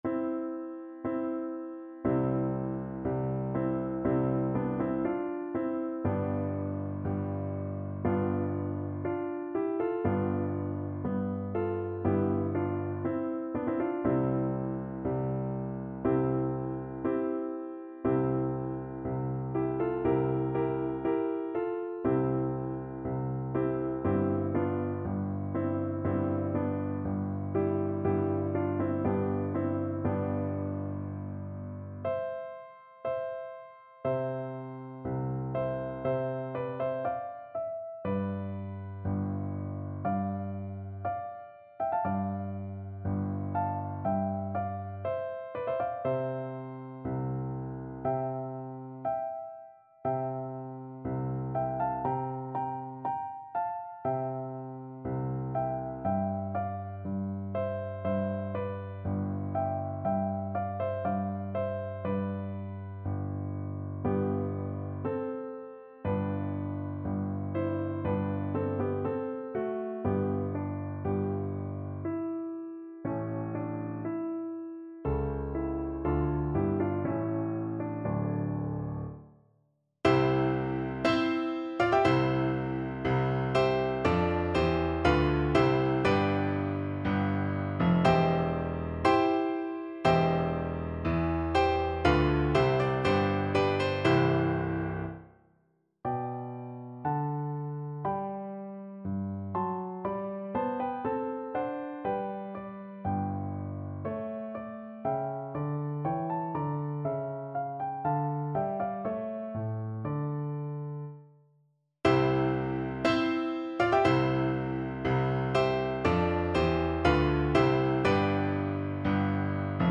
Classical Handel, George Frideric Funeral March from Saul Piano version
No parts available for this pieces as it is for solo piano.
4/4 (View more 4/4 Music)
C major (Sounding Pitch) (View more C major Music for Piano )
Slow =c.60
Classical (View more Classical Piano Music)